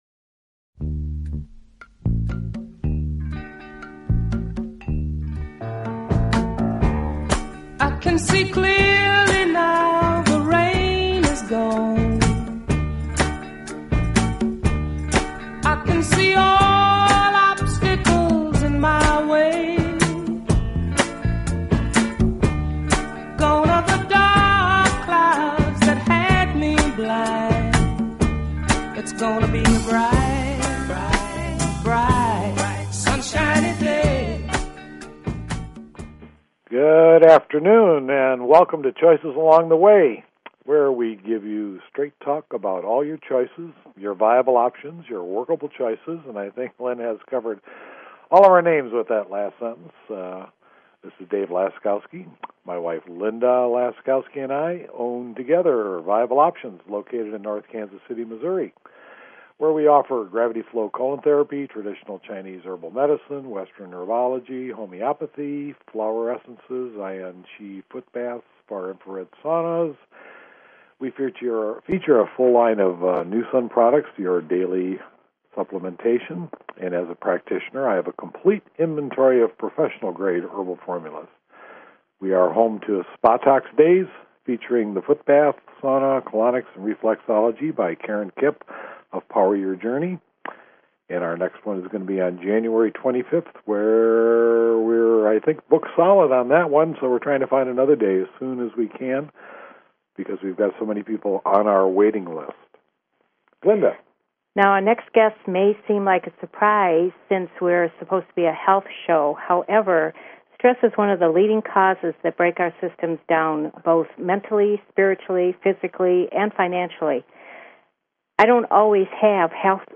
Talk Show Episode, Audio Podcast, Choices_Along_The_Way and Courtesy of BBS Radio on , show guests , about , categorized as